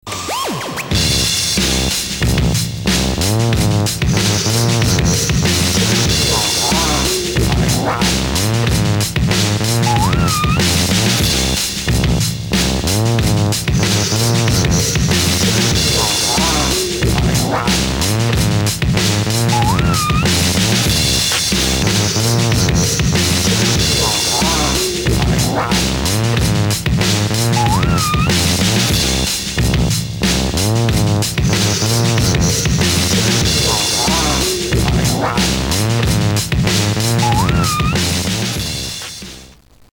МУЗЫКА ИЗ РЕКЛАМ (JINGLE)